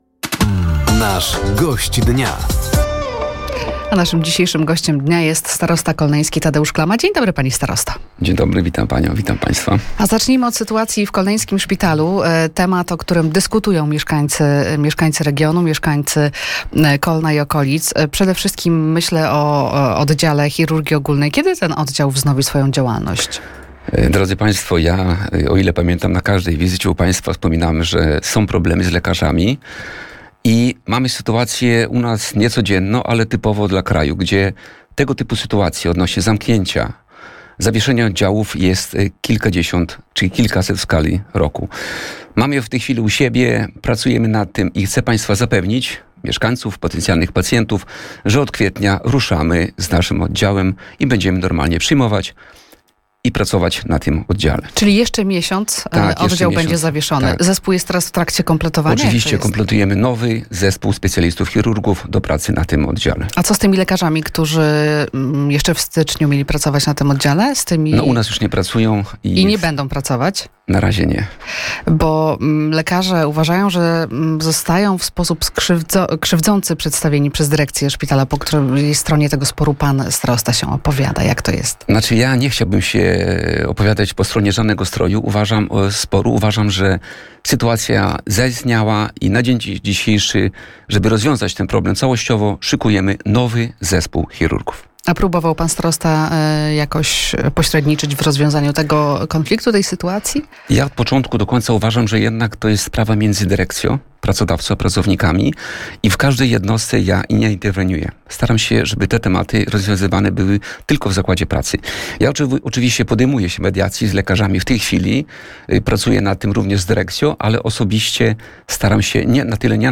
Gościem Dnia Radia Nadzieja był Tadeusz Klama – starosta kolneński. Tematem rozmowy był stan szpitala w Kolnie, modernizacja oraz rozbudowa dróg oraz kwalifikacja wojskowa.